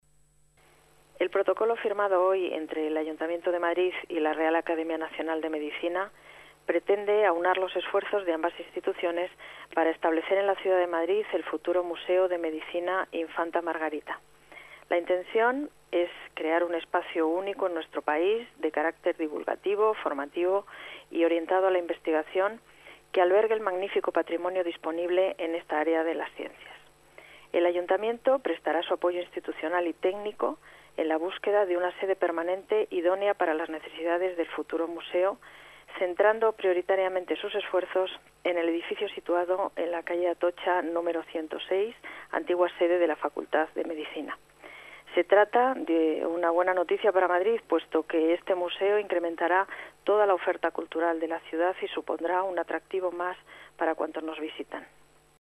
Nueva ventana:Declaraciones de la delegada de Urbanismo y Vivienda, Paz González